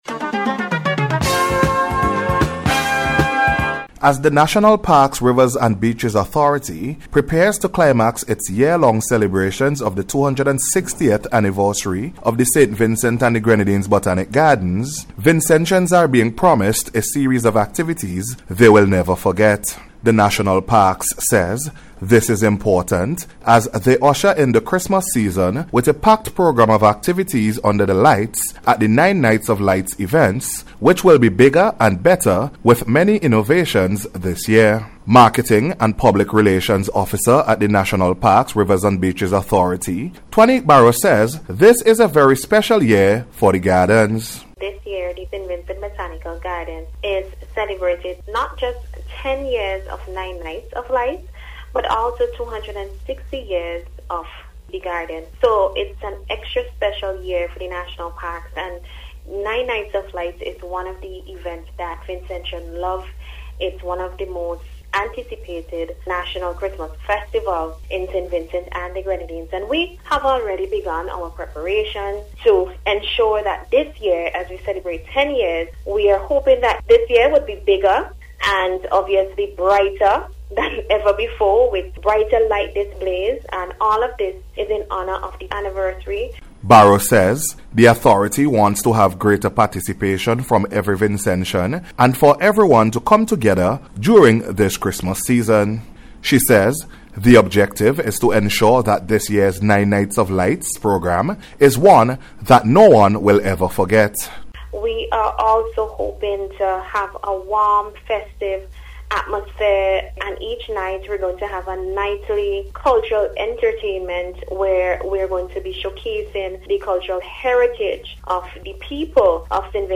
NBC’s Special Report – Thursday December 4th 2025
BOTANIC-GARDENS-GRAND-260TH-ANNIVERARY-WRAP-UP-REPORT.mp3